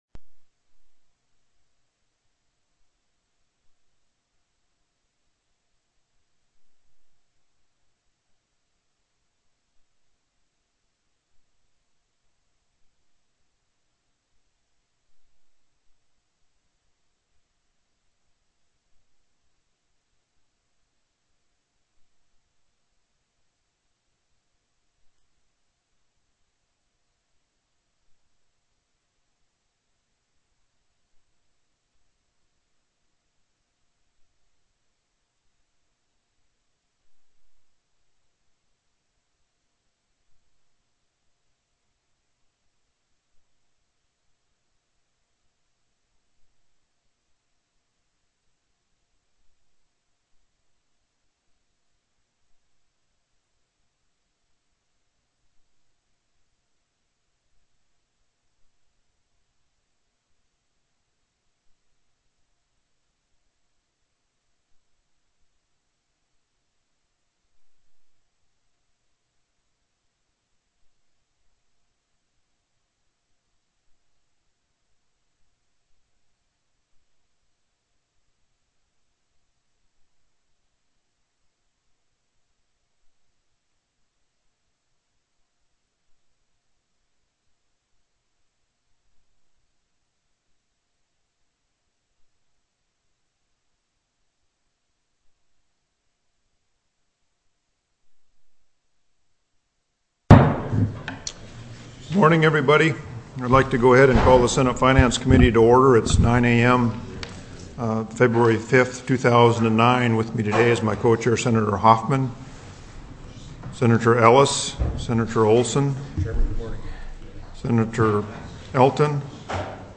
Co-Chair Stedman called the Senate Finance Committee meeting to order at 9:02 a.m.